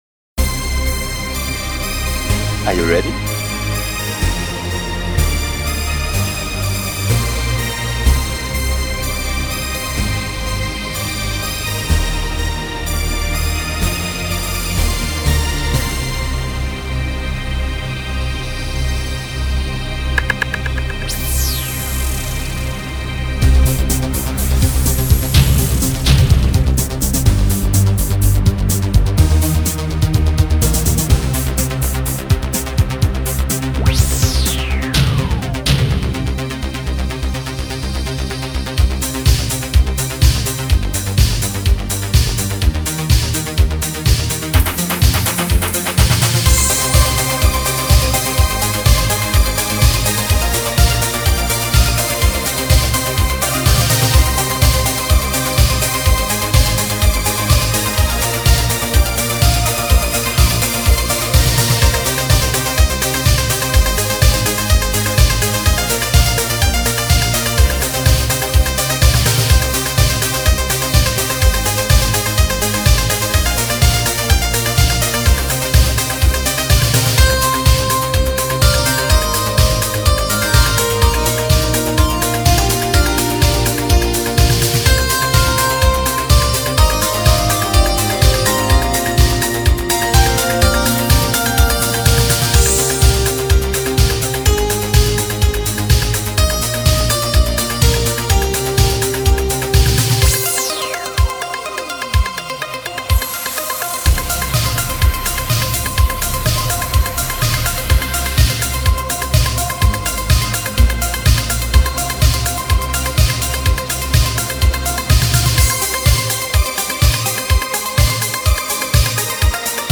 Genre: Spacesynth, Spacedisco.